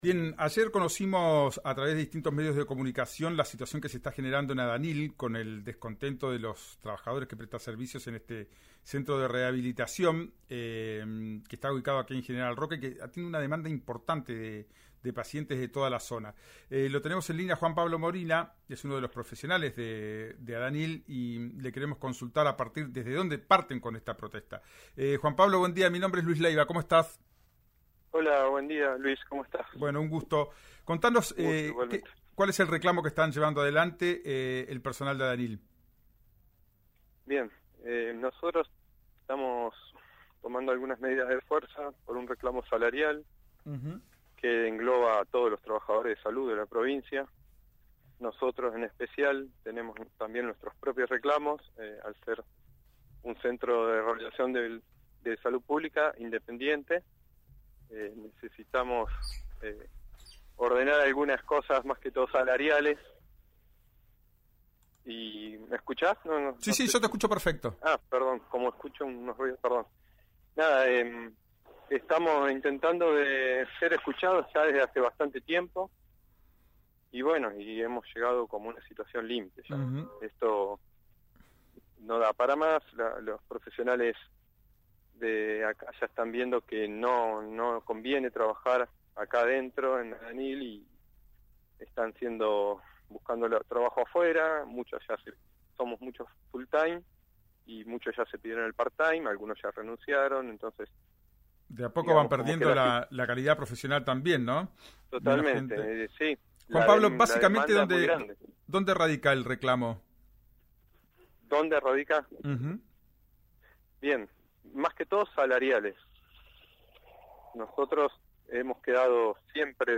en RÍO NEGRO RADIO